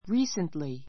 recently 中 A2 ríːsntli リ ー ス ン ト り 副詞 近頃 ちかごろ , 最近 ⦣ ふつう完了 かんりょう 形, 過去形とともに使う.